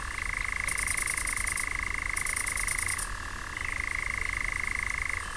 Di notte il Succiacapre continua la sua caccia e mentre è in volo lancia il suo inconfondibile
"canto" (231 KB), che in verità assomiglia più ad un rumore emesso da una raganella di legno.
succiacapre.wav